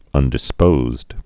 (ŭndĭ-spōzd)